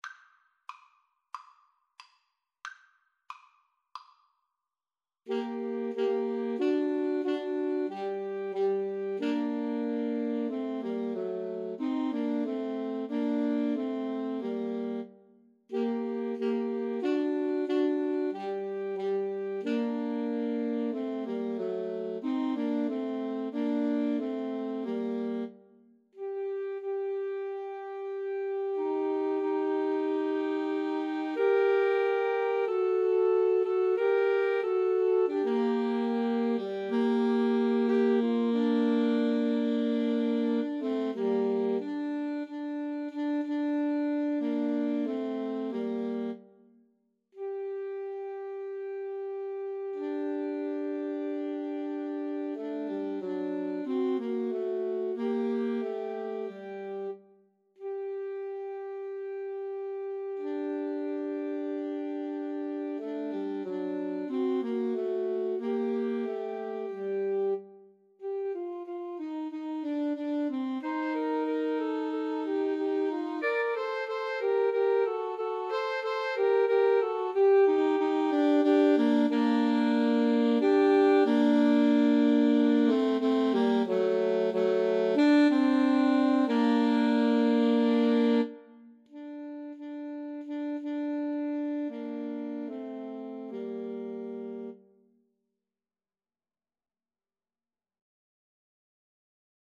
Alto Sax Trio version
Andante cantabile ( = c. 92)